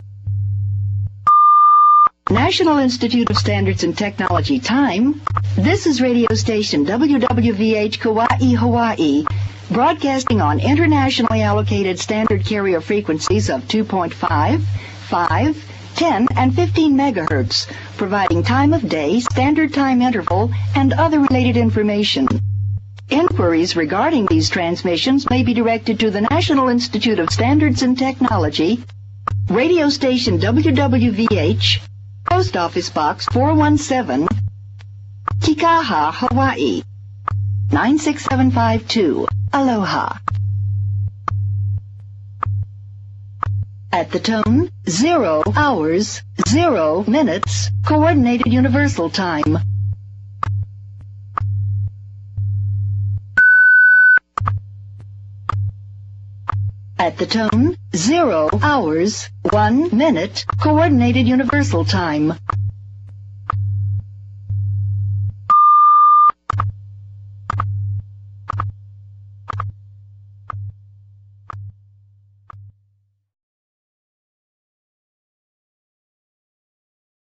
(Highly edited) Direct Audio from the WWVH "air-chain" (2000)***
The "voice" of WWVH is Mrs. Jane Barbe (now deceased)